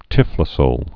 (tĭflə-sōl)